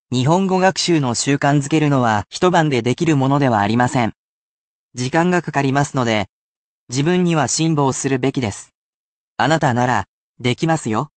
And, you are sure to enjoy our resident computer robot, here to help you pronounce words along the way.